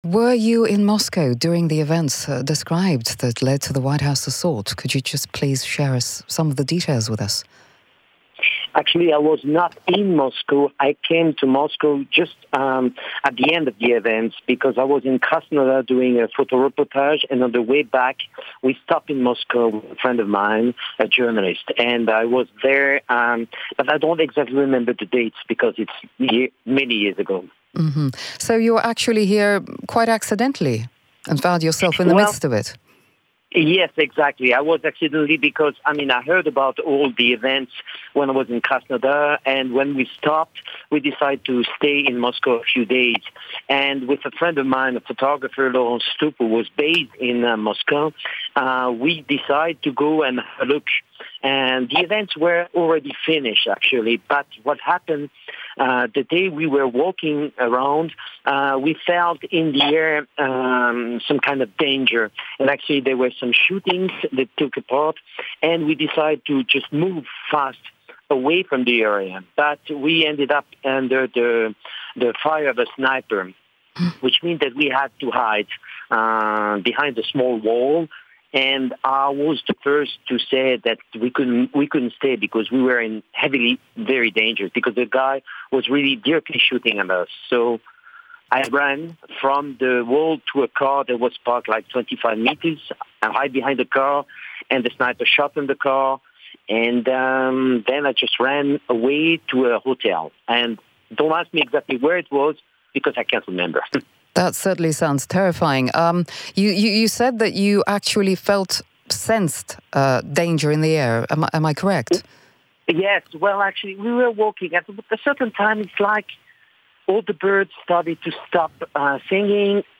An interview on Radio Sputnik International.